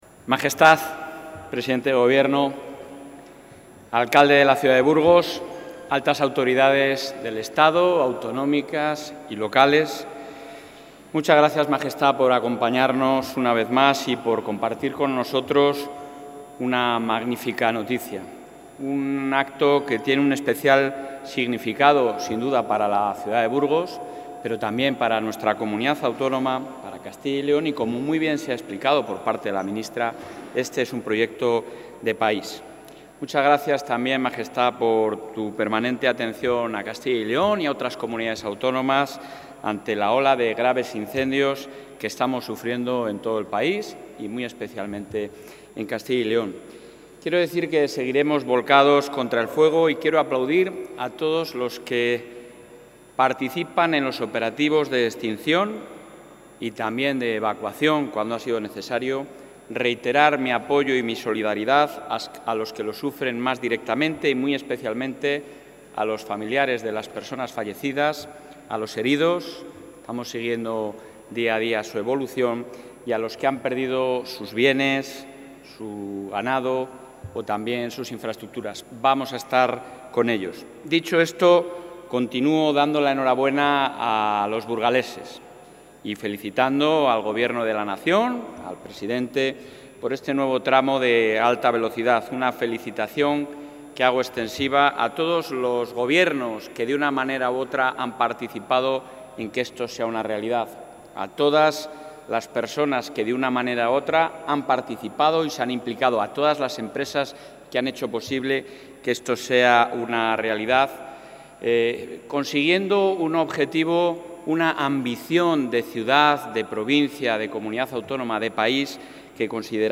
En la inauguración de la línea de Alta Velocidad Burgos-Madrid, el presidente de la Junta de Castilla y León, Alfonso...
Intervención del presidente de la Junta.